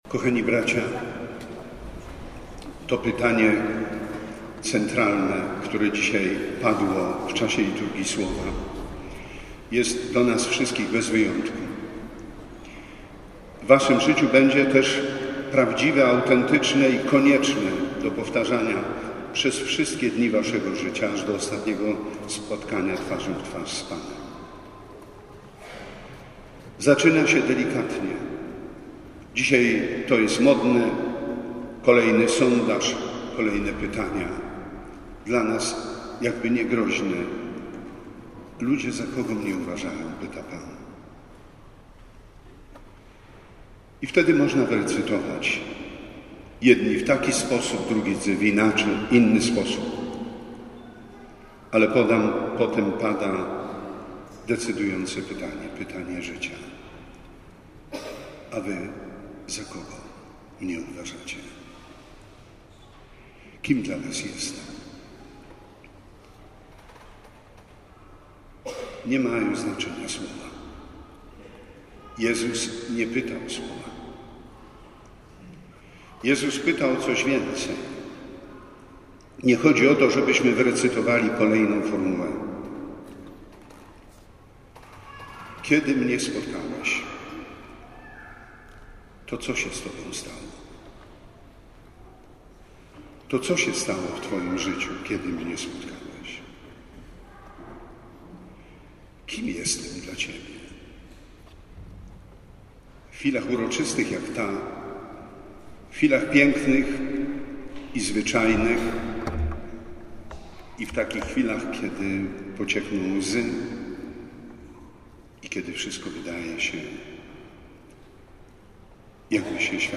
W kołobrzeskiej bazylice bp Edward Dajczak udzielił siedmiu diakonom święceń prezbiteratu.
1.06 Homilia bp Dajczak prezbiterat.mp3